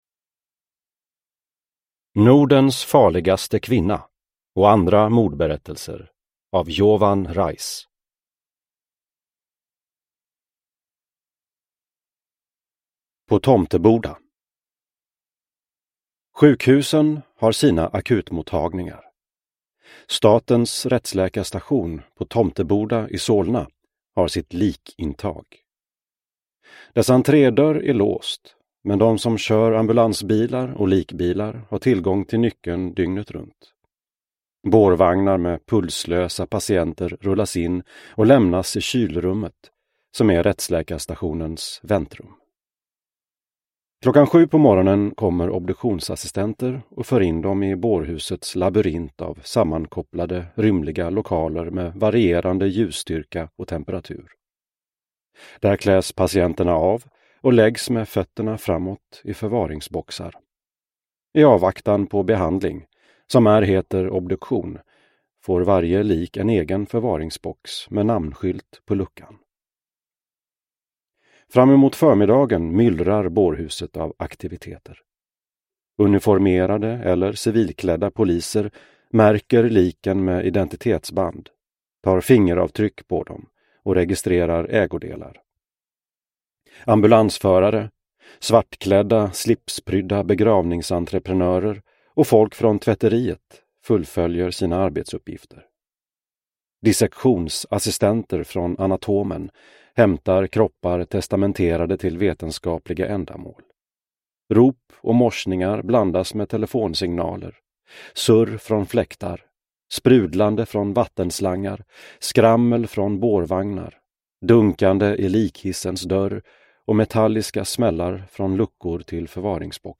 Nordens farligaste kvinna och andra mordberättelser – Ljudbok – Laddas ner